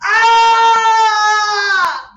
scream7.mp3